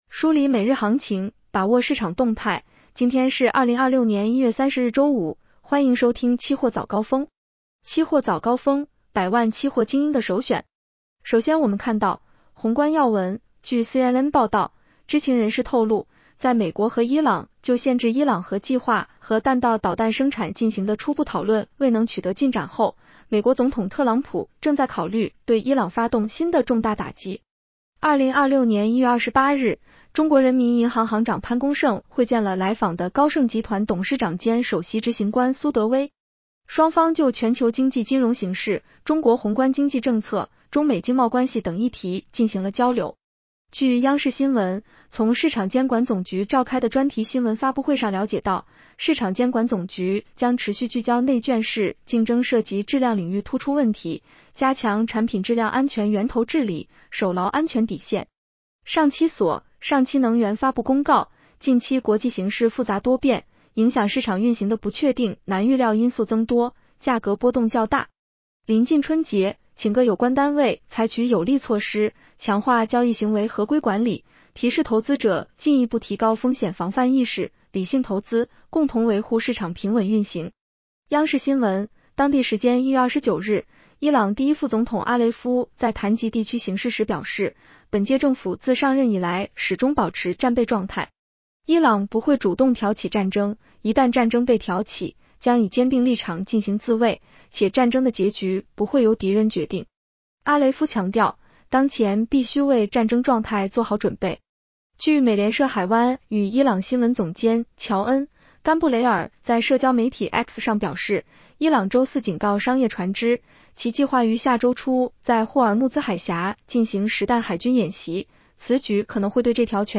期货早高峰-音频版 女声普通话版 下载mp3 热点导读 1.